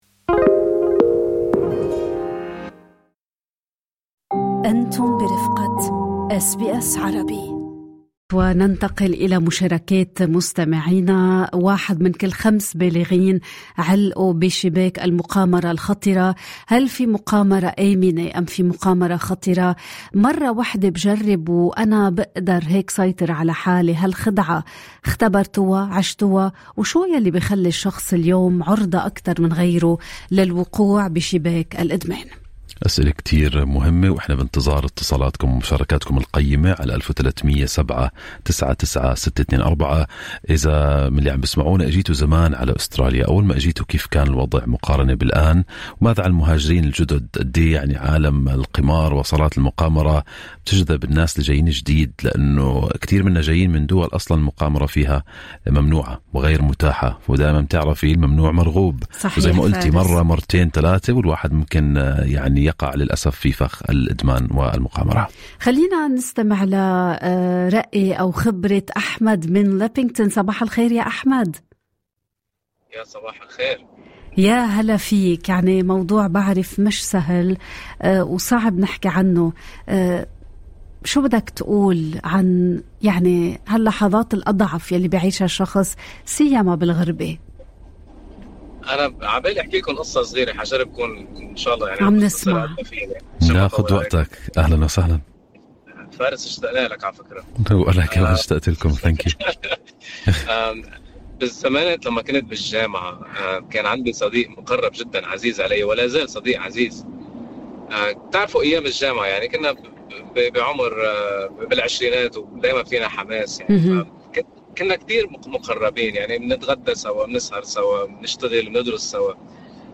تُنفق أستراليا ما يقارب 8 ملايين دولار كل ساعة على المقامرة، فيما واحد من كل خمسة بالغين عالقون في مستويات خطرة من الإدمان. شارك مستمعون من خلفيات مختلفة تجاربهم الشخصية، لتكشف شهاداتهم أن المقامرة ليست مجرد لعبة، بل فخ يمتد أثره من خسائر مالية إلى أزمات عائلية وعزلة اجتماعية.
ففي نقاش مفتوح ببرنامج صباح الخير أستراليا تقاطعت شهادات المهاجرين بين تجارب شخصية أليمة وقصص إنقاذ جاءت في اللحظة الأخيرة.